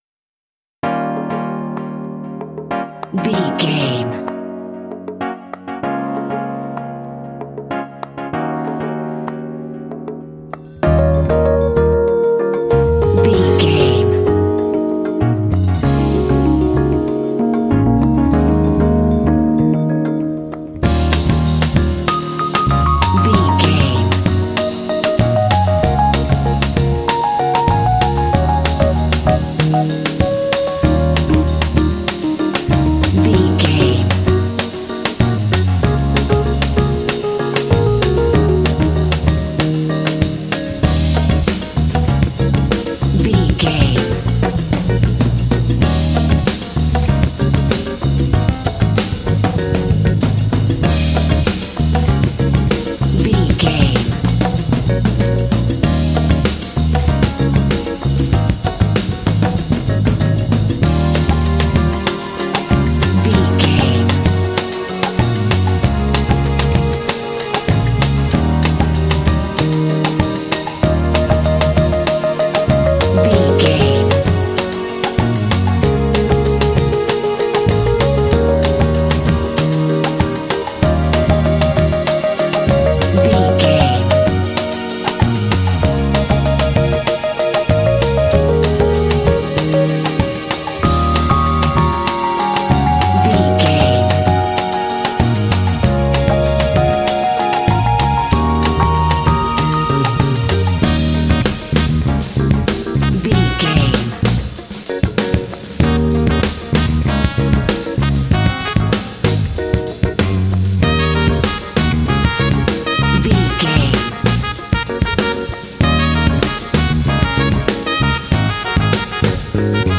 Aeolian/Minor
E♭
smooth
percussion
electric guitar
electric piano
strings
bass guitar
drums
saxophone
trumpet
Funk
latin
Lounge
downtempo